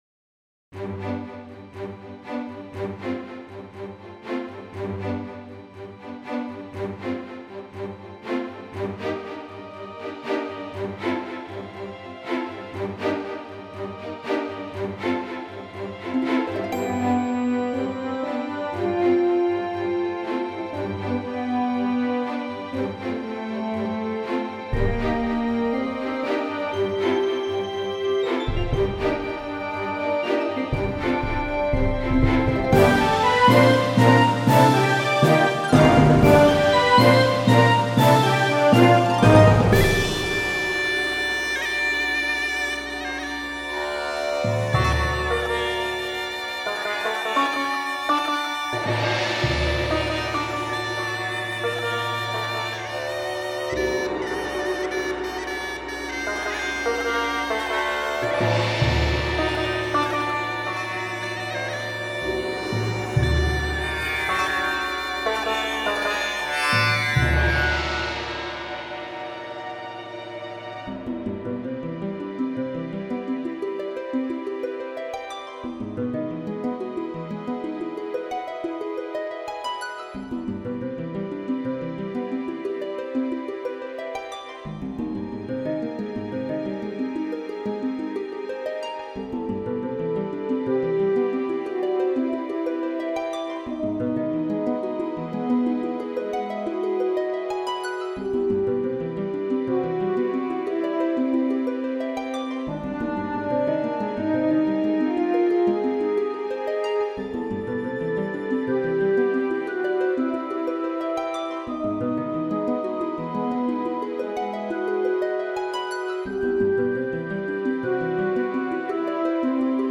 Pour la quatrième, la marche épique VGM, la touche orientale, je suis... je suis ?
belle orchestration, les éléments voyages un peu, le debut assez enlevé avec les ostinato de cordes pour l'eau ou la terreon arrive en terre orientale pour le feu qui danse la harpe pour l'air c'est très agréable a écouter, bravo /clap /clap /clap